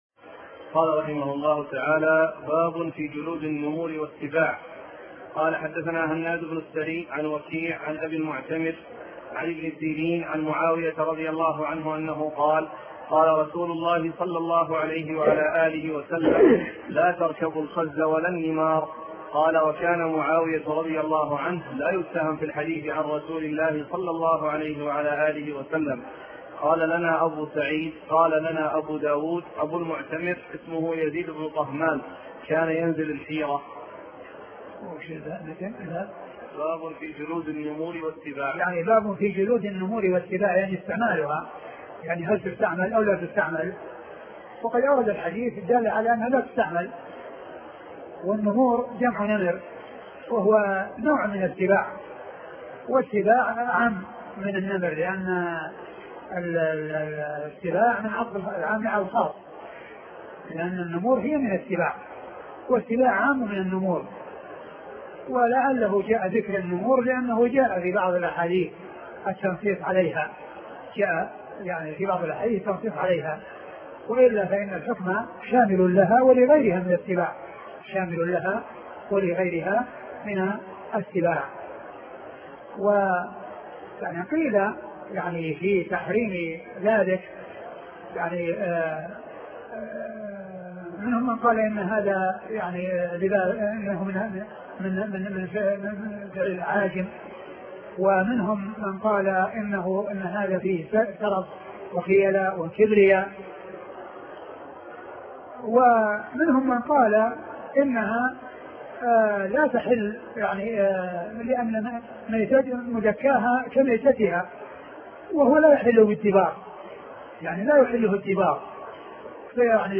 أرشيف الإسلام - أرشيف صوتي لدروس وخطب ومحاضرات الشيخ عبد المحسن العباد